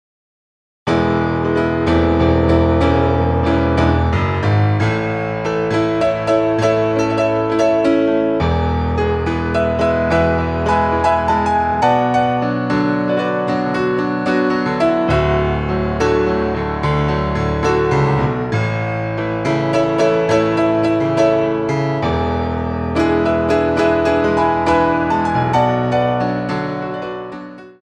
Grand Battement en Cloche